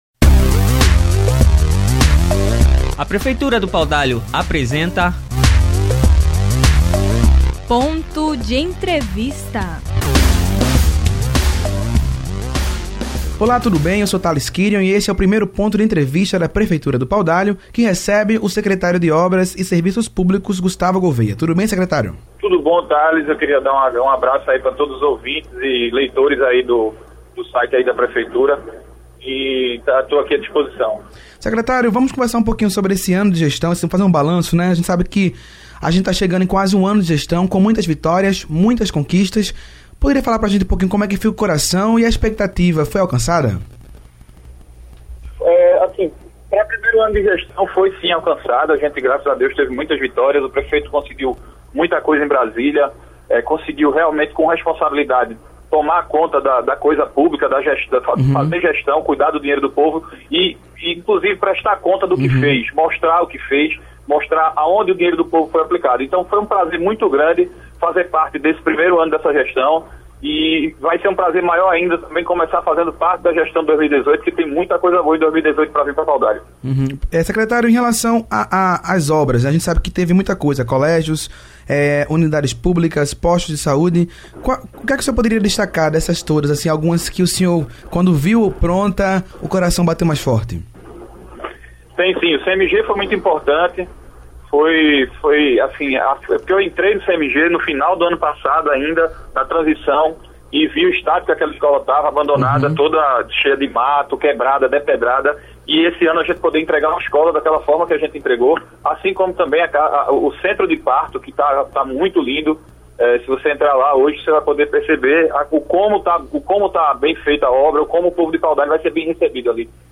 Em entrevista nesta quarta-feira (27) ao programa Ponto de Entrevista da Prefeitura do Paudalho, o secretário de Obras e Serviços Públicos, Gustavo Gouveia, comemorou o primeiro ano de gestão.